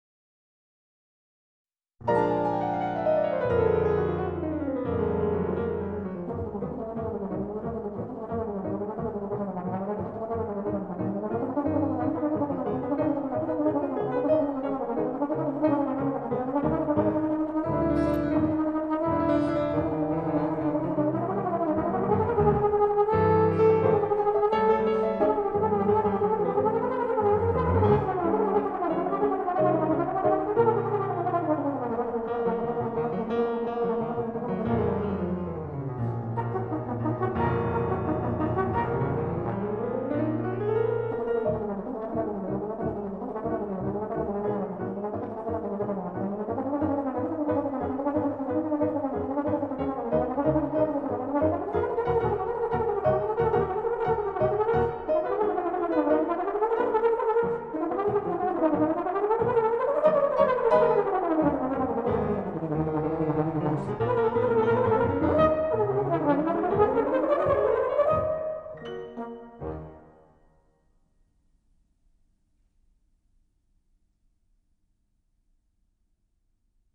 专辑风格:古典音乐
演奏乐器:长号, 钢琴